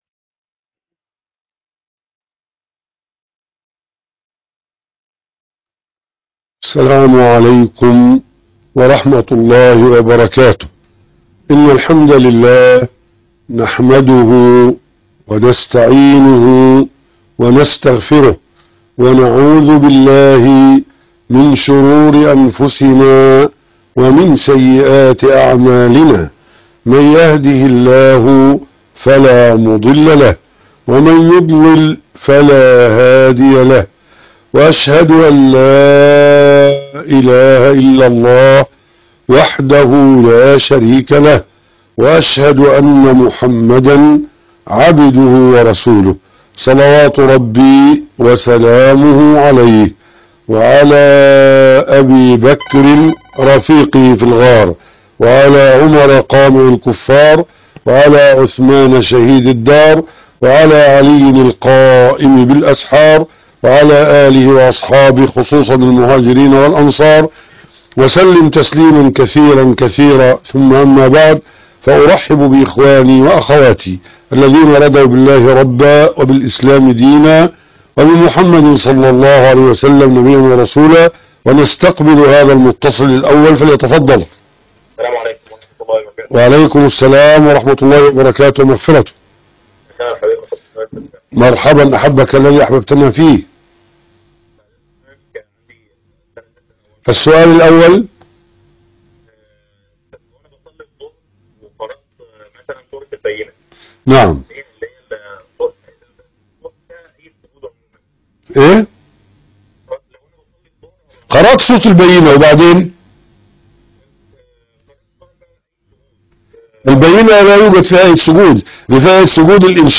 لقاء الفتاوى